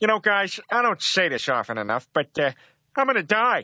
Worms speechbanks
Byebye.wav